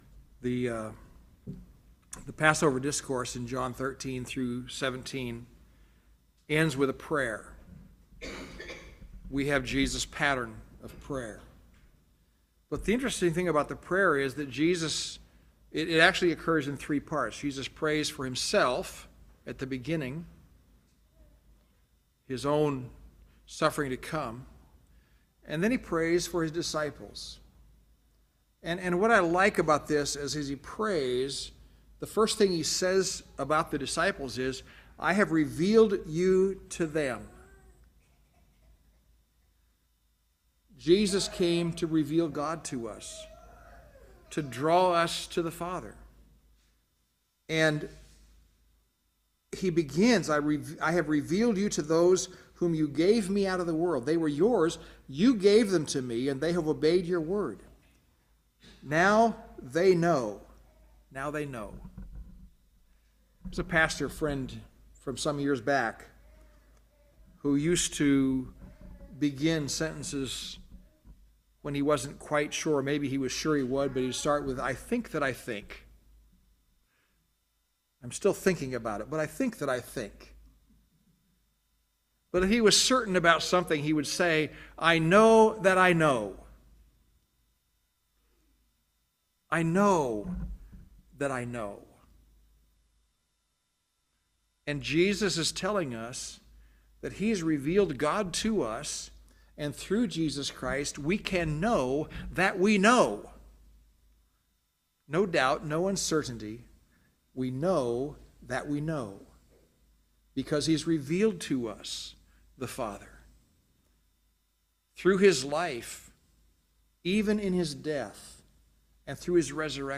A question came to mind as I was studying for this sermon.